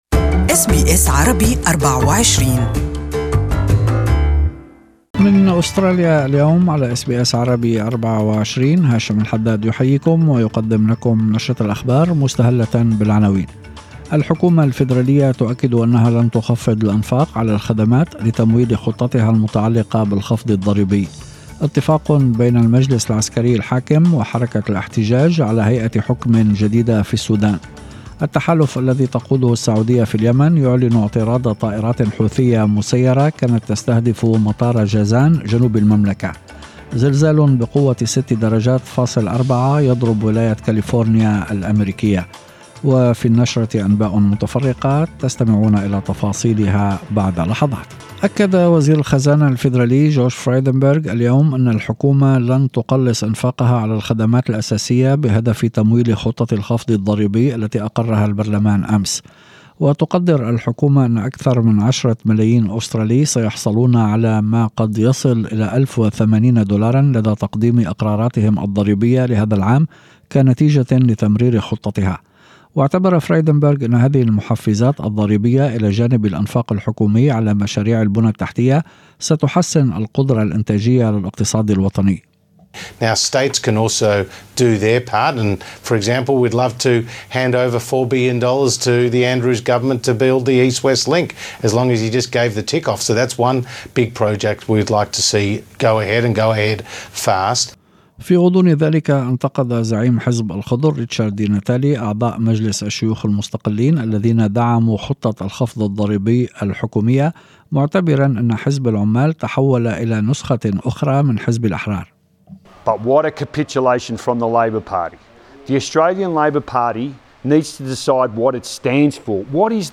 هذه الأخبار من نشرة الأنباء المسائية التي يمكن الإستماع اليها في الرابط الصوتي أعلى الصفحة.